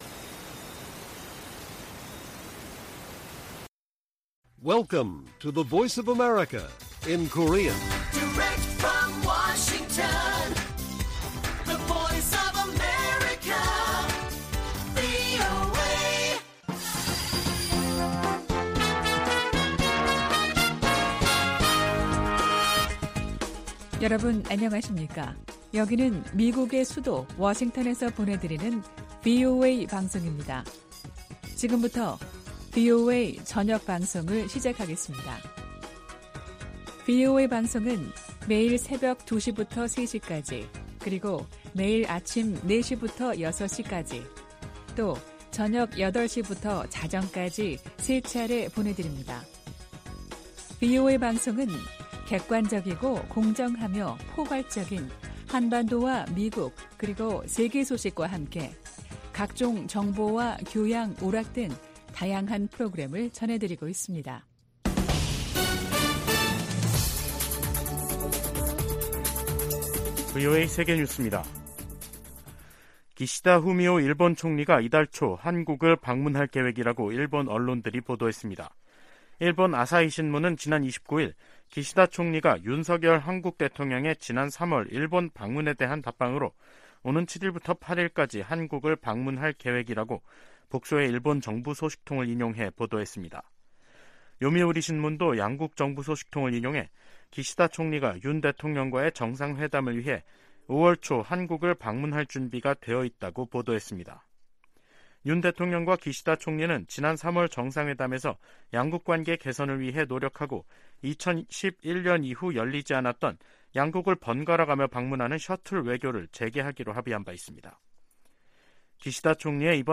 VOA 한국어 간판 뉴스 프로그램 '뉴스 투데이', 2023년 5월 1일 1부 방송입니다. 김여정 북한 노동당 부부장이 ‘결정적 행동’을 언급해 대형 도발에 나설 가능성을 시사했습니다. 윤석열 한국 대통령이 하버드대 연설에서 워싱턴 선언에 포함된 한국의 의무를 거론하며 독자 핵개발에 선을 그었습니다. 12년 만에 이뤄진 한국 대통령의 미국 국빈 방문은 한층 강화된 양국 관계를 보여줬다고 캐서린 스티븐스 전 주한 미국대사가 평가했습니다.